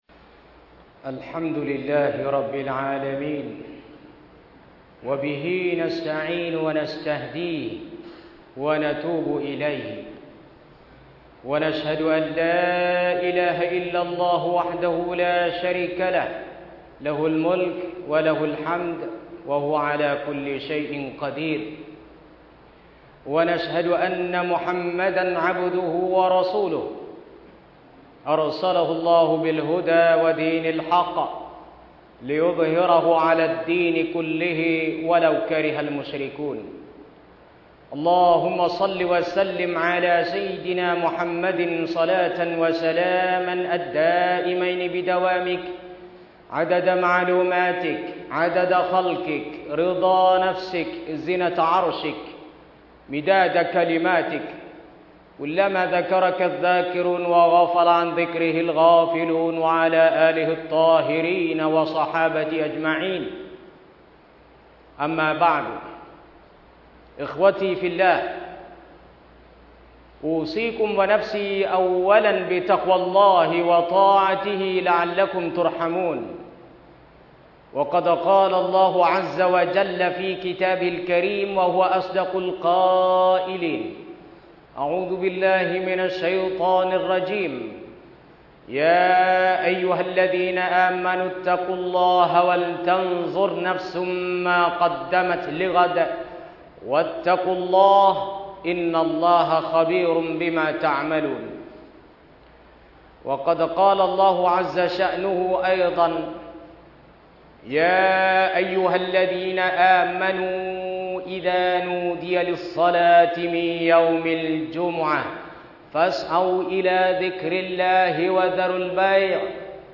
มัสยิดกลาง จังหวัดชลบุรี ดาวน์โหลดไฟล์เสียง
คุตบะฮฺวันศุกร์ : ความประเสริฐของวันศุกร์ [EP.1]